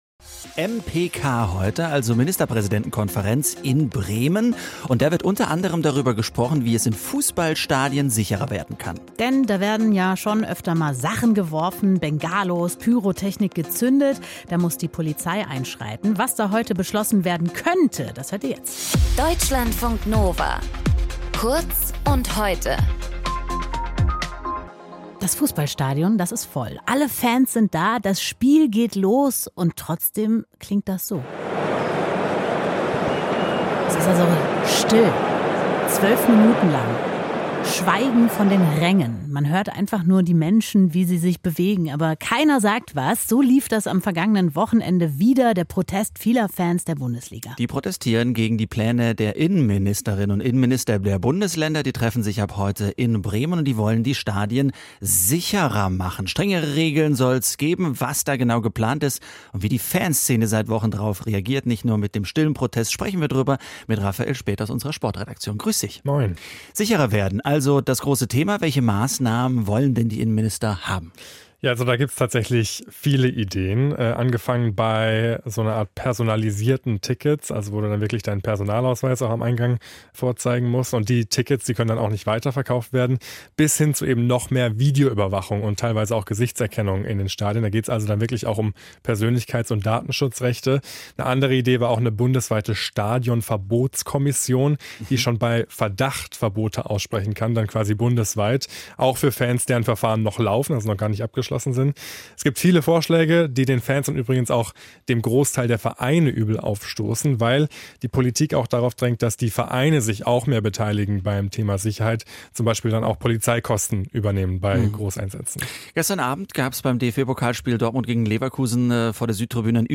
In dieser Folge mit:
Moderation:
Gesprächspartner: